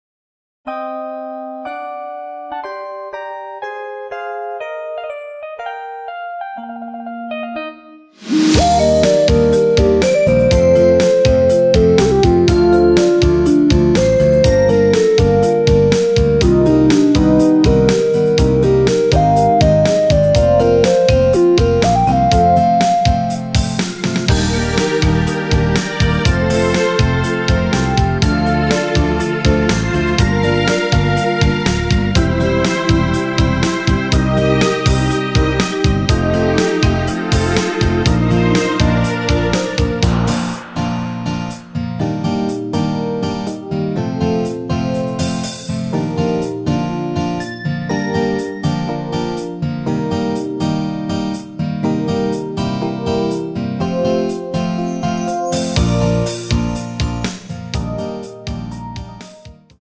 원키에서 (-3)내린 MR 입니다.
원곡의 보컬 목소리를 MR에 약하게 넣어서 제작한 MR이며